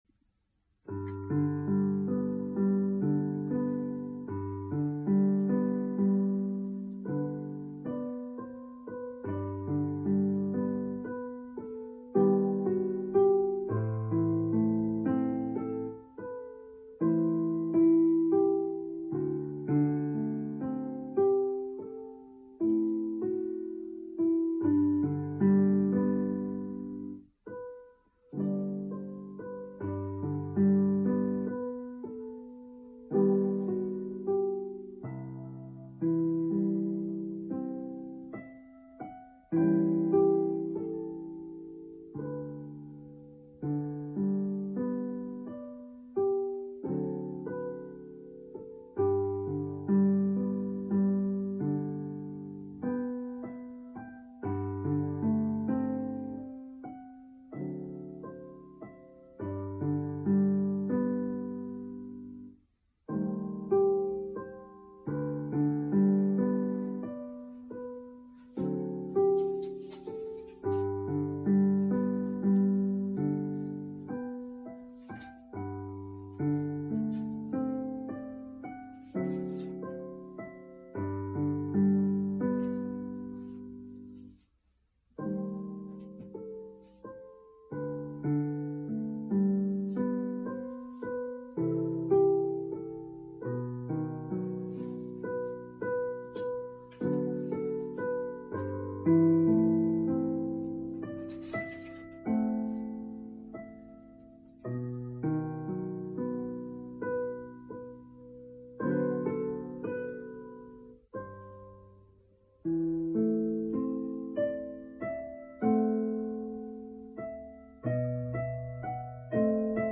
C. De Crescenzo (1847-1911): Notturno. Prima Carezza Op. 120 N. 1 (Első látásra, eddig soha nem tanult darab /A prima vista, finora mai studiata composizione)>> (Néhány órája fedeztem fel az újabb kották között.../ Da qualche ora l'ho scoperta tra le spartiture  più recenti...